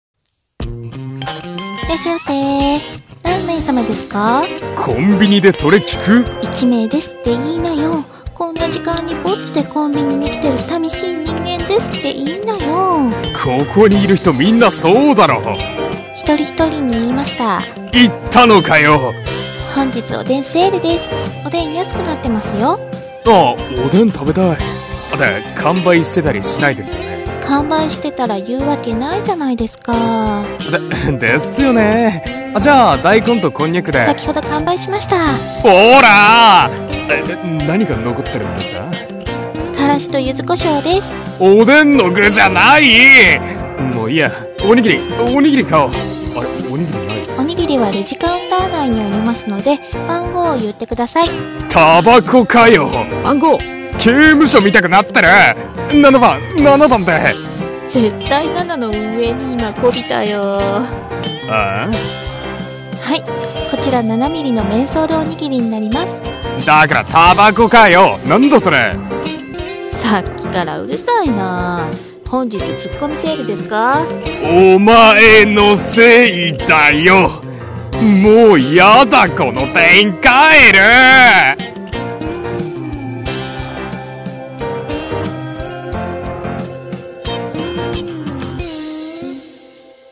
ギャグ声劇 ここのコンビニの店員がヤバい